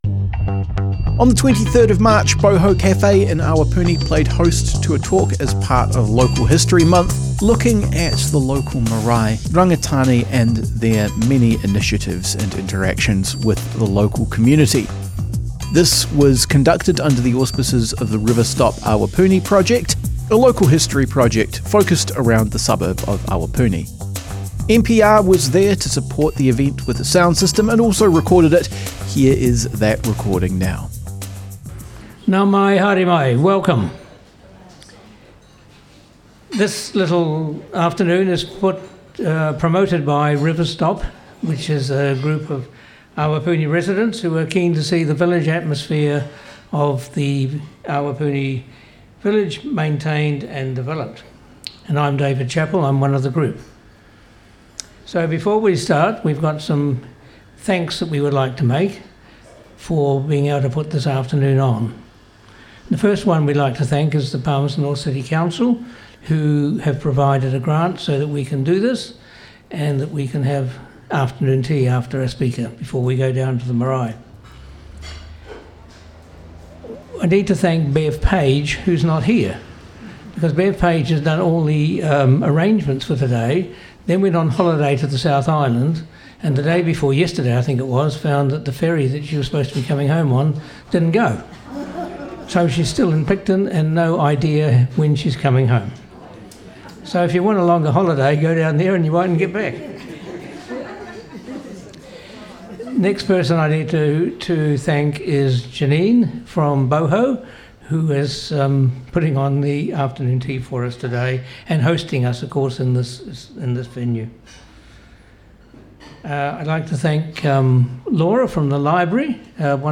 00:00 of 00:00 Add to a set Other Sets Description Comments Tea, tales and tour with River Stop Awapuni Object type Audio More Info → Description Audio captured by Manawatu People's Radio at a local history month event, organised by River Stop Awapuni. The talk was planned to share the history of the site now housing Te Hotu Manawa O Rangitāne O Manawatū Marae and Best Care Whakapai Hauora, on Maxwells Line.